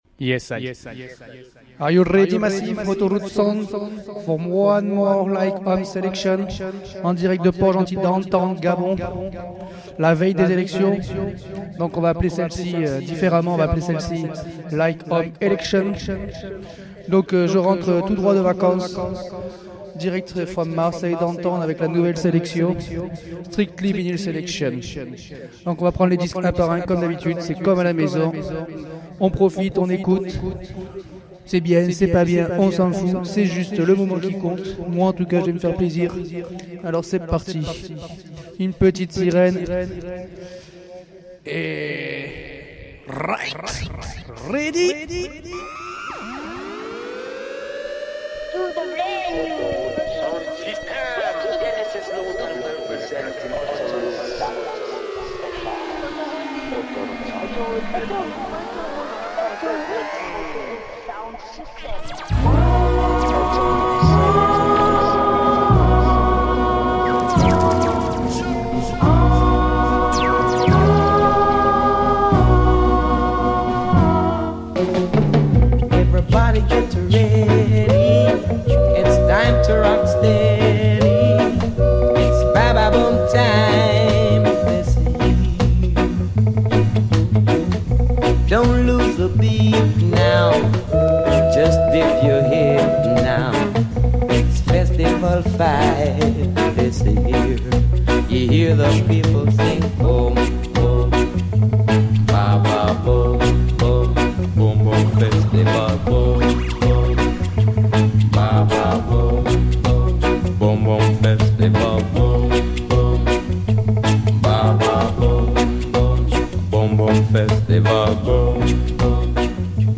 Strictly Vinyl Selection Enjoy Massive